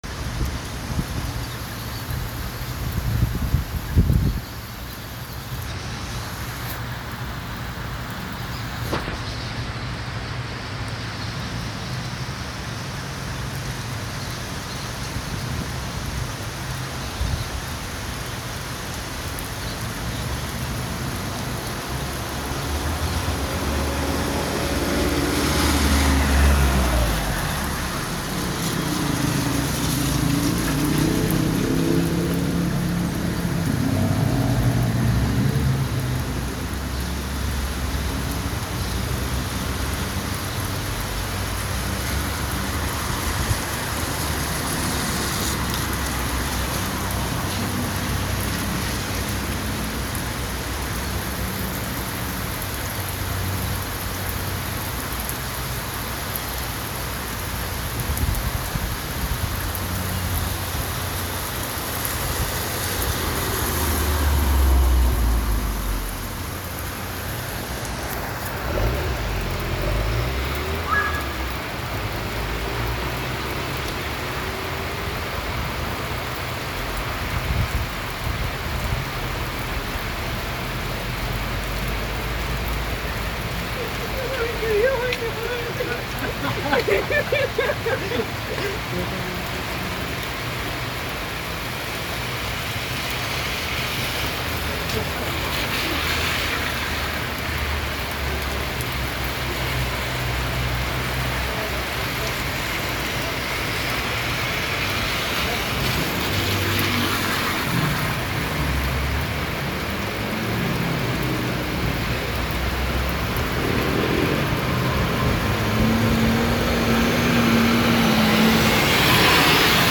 Alajuela lluviosa ALAJUELA